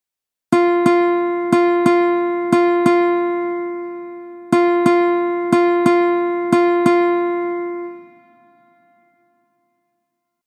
Chant pour poter